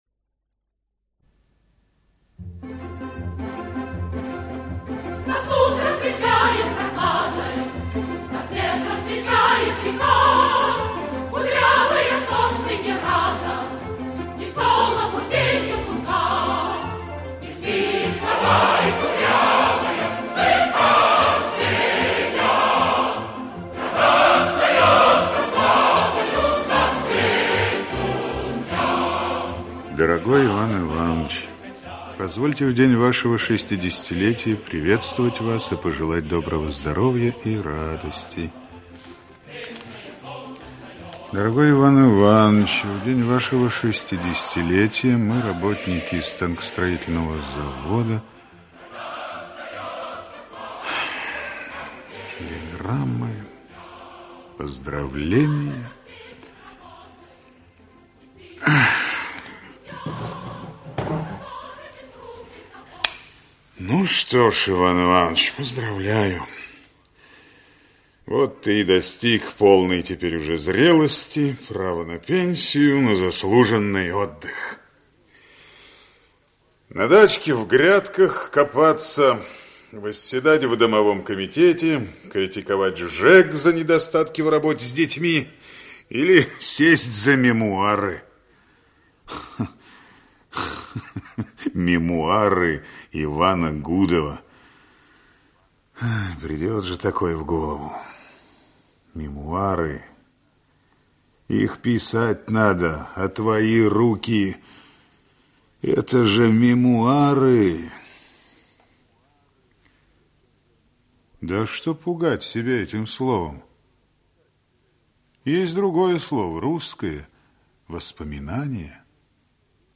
Аудиокнига Я – рабочий. Часть 1. «Жизни навстречу» | Библиотека аудиокниг
«Жизни навстречу» Автор Петр Алексеевич Попогребский Читает аудиокнигу Алексей Грибов.